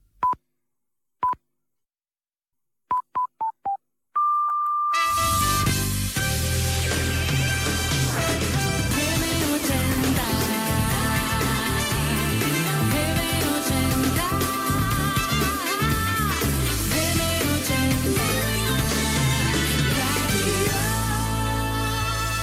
Senyals horaris i indicatiu de la ràdio